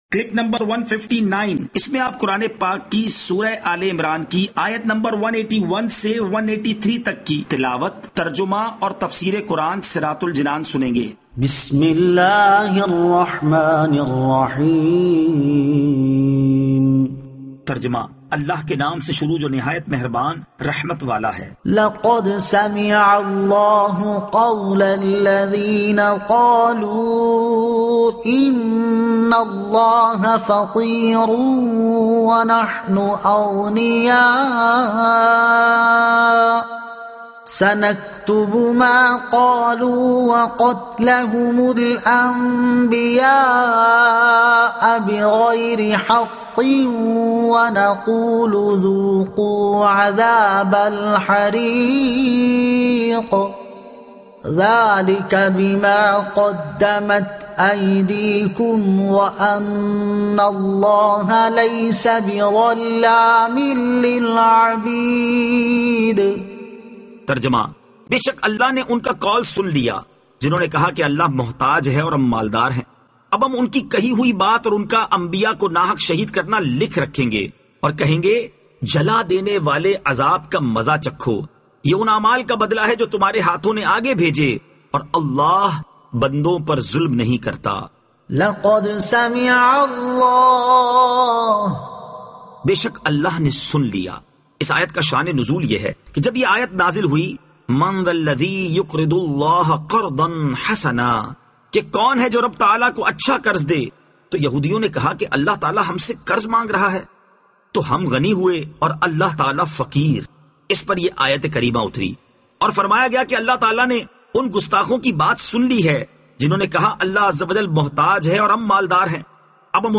Surah Aal-e-Imran Ayat 181 To 183 Tilawat , Tarjuma , Tafseer